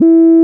sine12 e4.wav